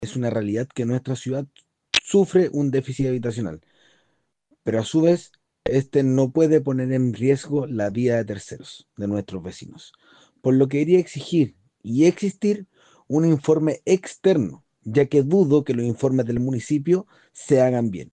Por su lado, el concejal Andrés Solar, comentó a Radio Bío Bío que el proyecto se viene gestando hace años como una solución a la crisis inmobiliaria en la ciudad.